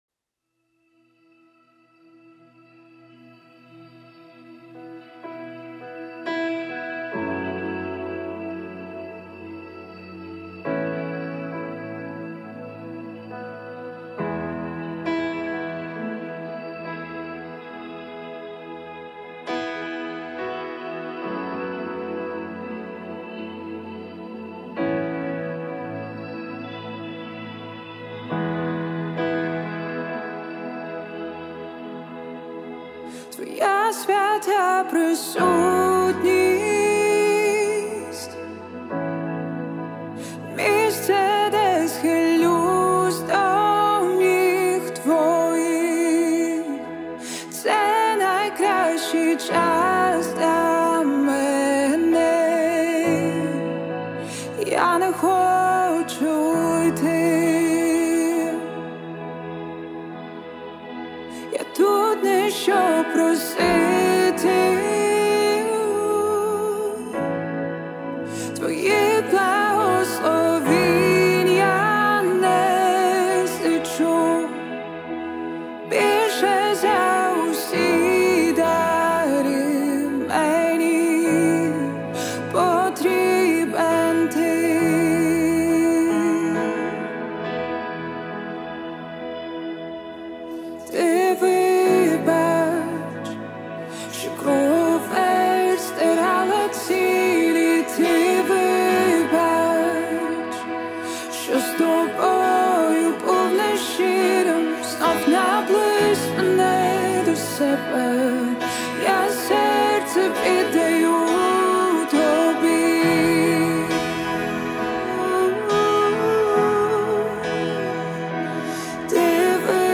песня
1894 просмотра 422 прослушивания 16 скачиваний BPM: 136